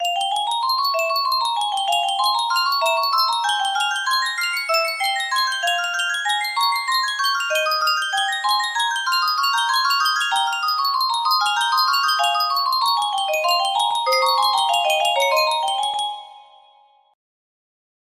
Yunsheng Custom Tune Music Box - Bach Invention No. 4 music box melody
Full range 60